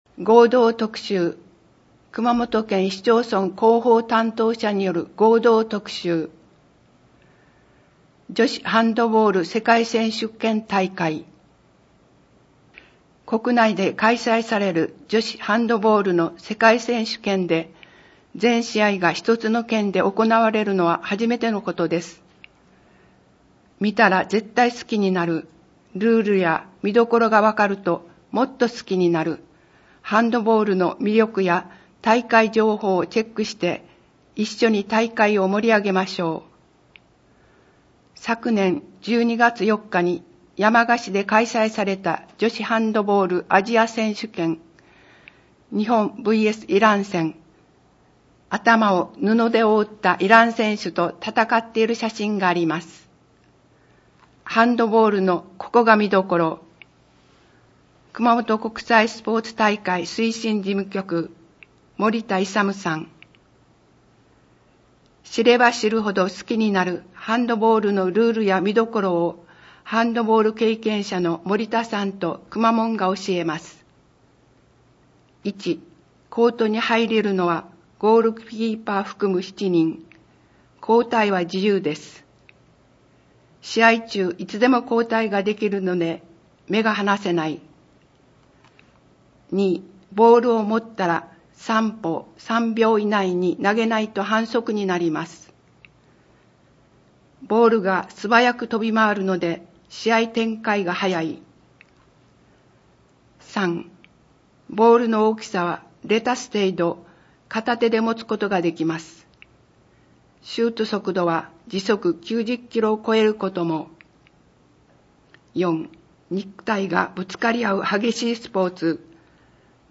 広報こうし平成31年2月号 音訳版